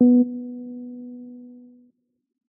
Tone.js/examples/audio/casio/B1.mp3 at 8881b7f5bdd7f9c2afd09bcf8cbdd8c1c042120a